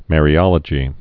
(mârē-ŏlə-jē)